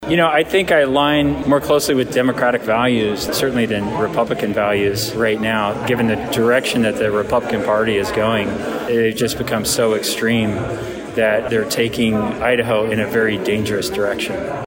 Rognstad touted his bona fides as a fourth-generation Idahoan, a small business owner, and a University of Idaho graduate during his campaign kick-off, and later on commented on why he’s running as a Democrat.